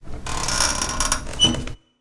crowbar.ogg